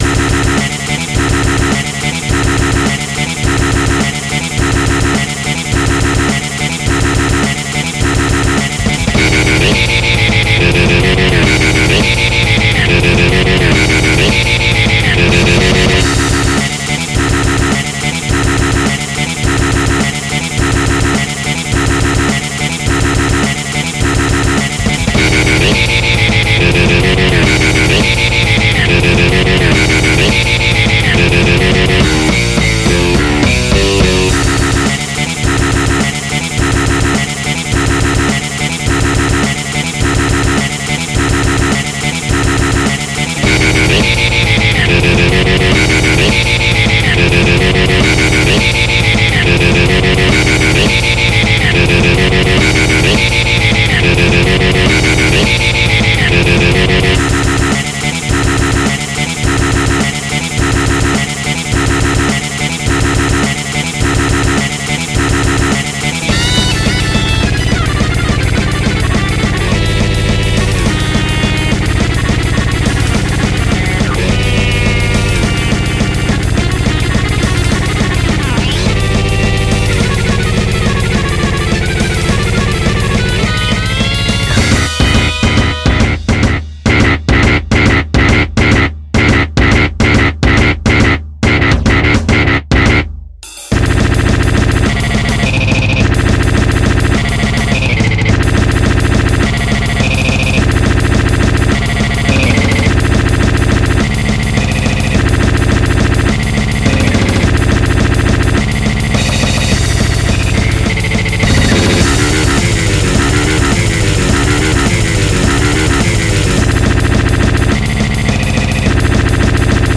MIDI 80.89 KB MP3